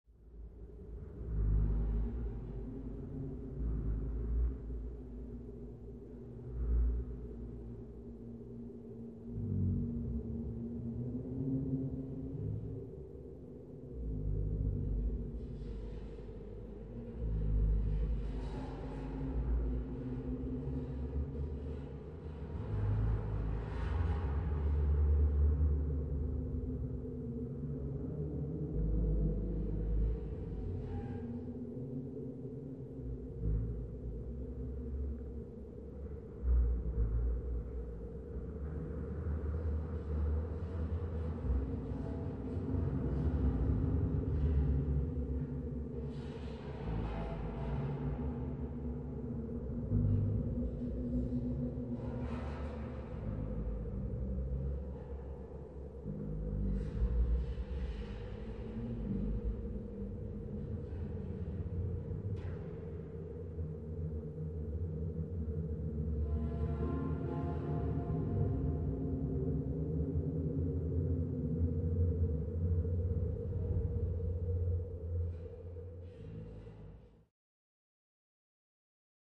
Submarine Ambience
Tonal Hum, Ventilation Noise On Ship.